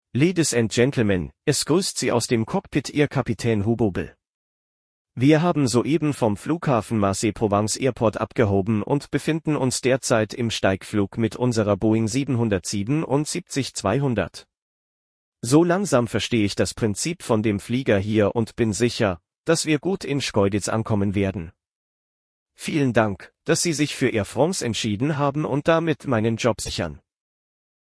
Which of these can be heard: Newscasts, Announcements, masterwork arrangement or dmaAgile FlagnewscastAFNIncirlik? Announcements